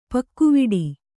♪ pakkuviḍi